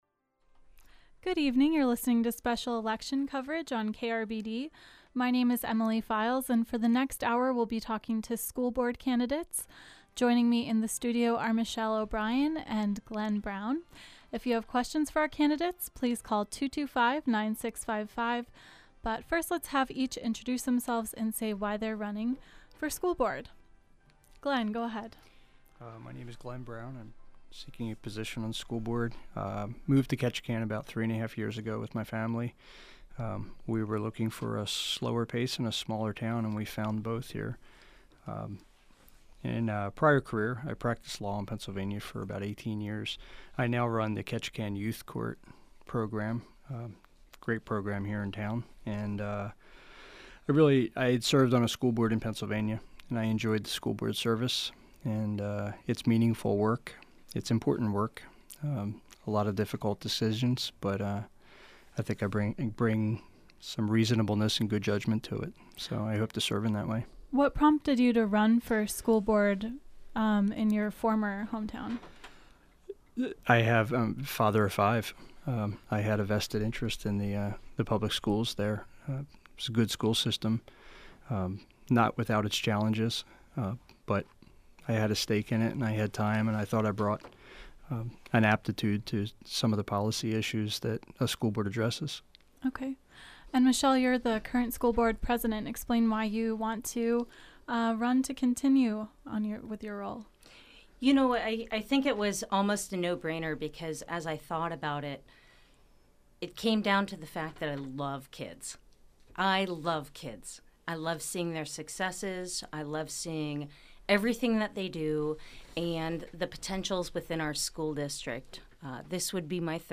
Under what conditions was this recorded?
The two candidates for Ketchikan School Board talked about their hopes for the school district at a KRBD call-in forum Monday evening.